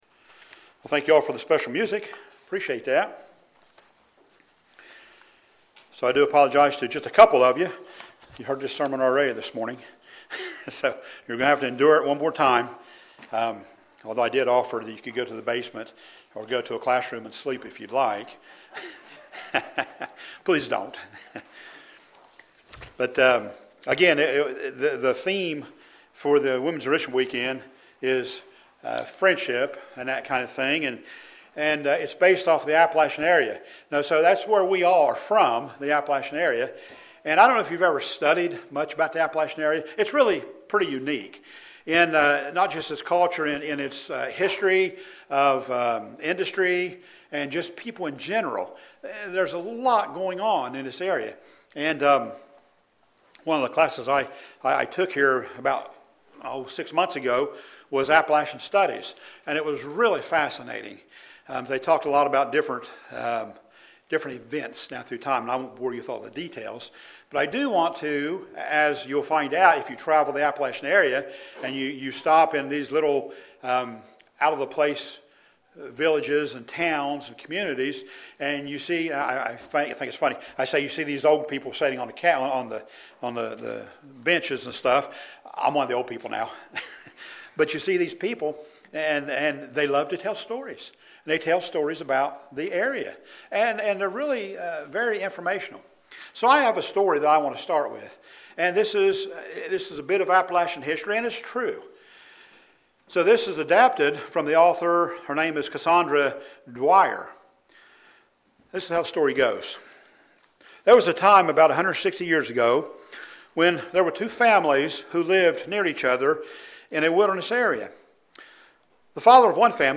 Sermons
Given in Paintsville, KY Portsmouth, OH